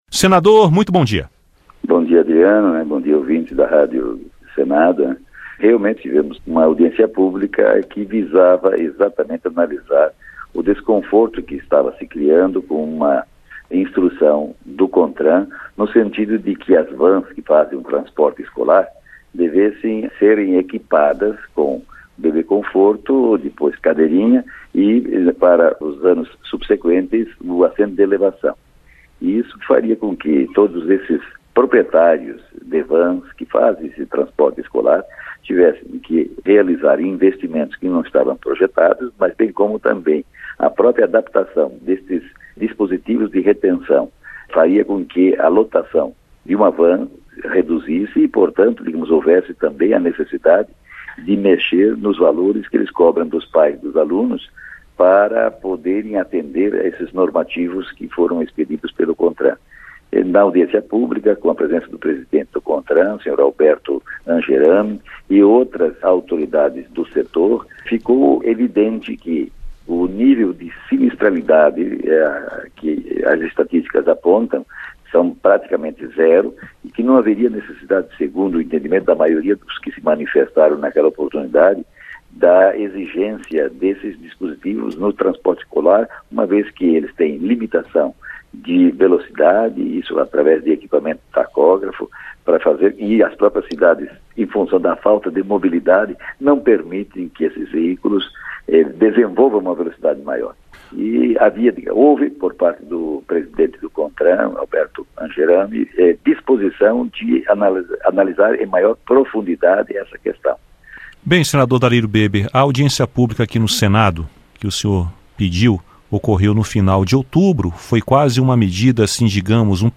Dalírio Beber comenta obrigatoriedade de cadeirinha para crianças em transporte escolar